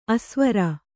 ♪ asvara